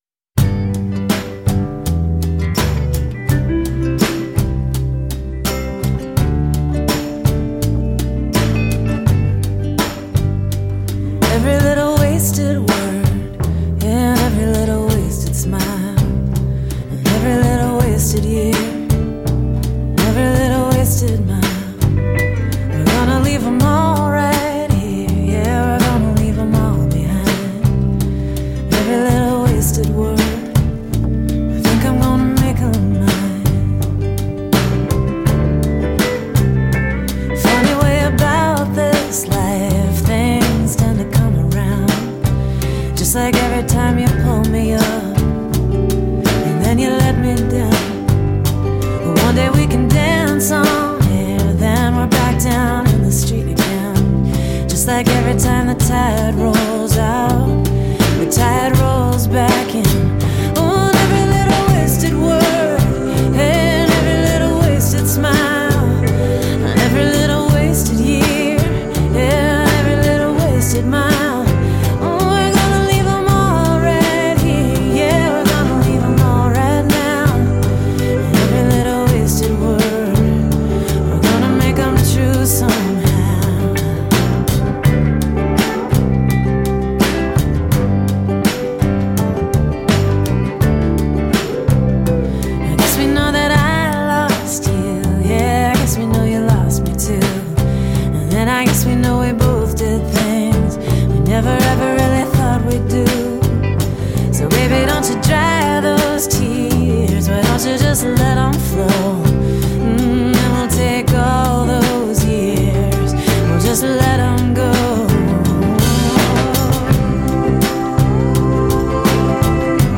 Boston singer-songwriter